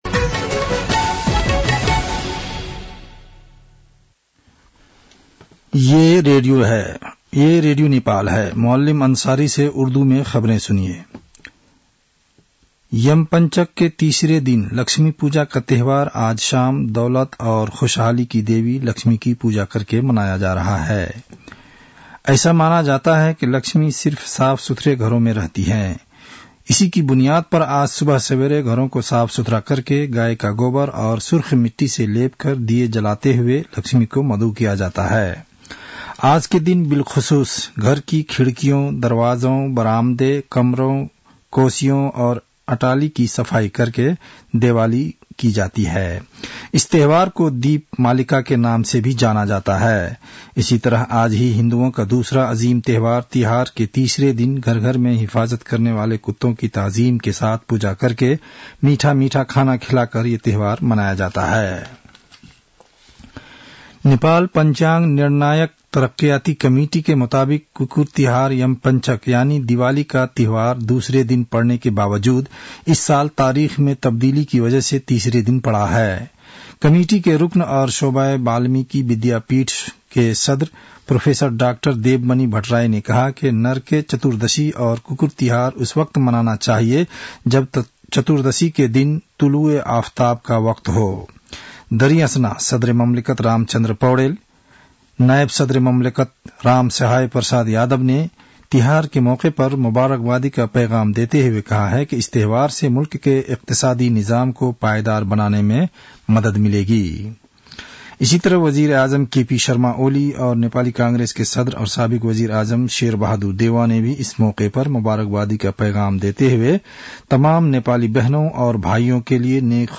उर्दु भाषामा समाचार : १६ कार्तिक , २०८१
Urdu-news-7-15.mp3